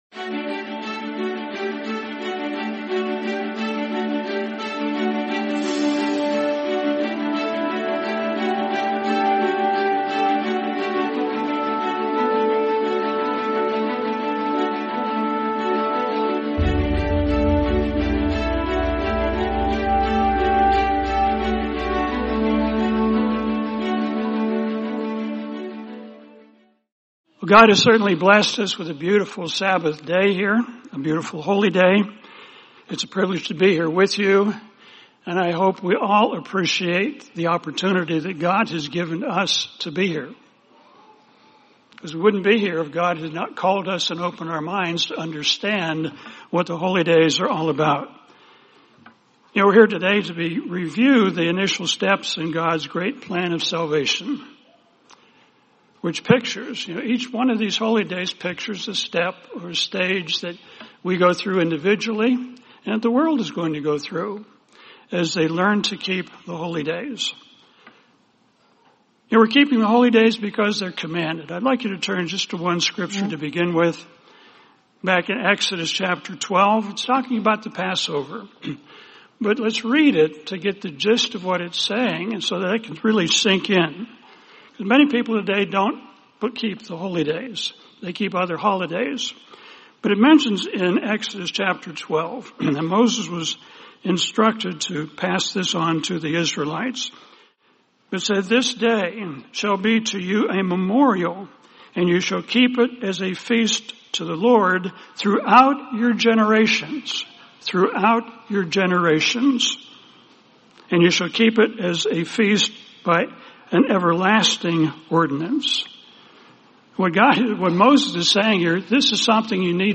Changes Overcomers Must Make | Sermon | LCG Members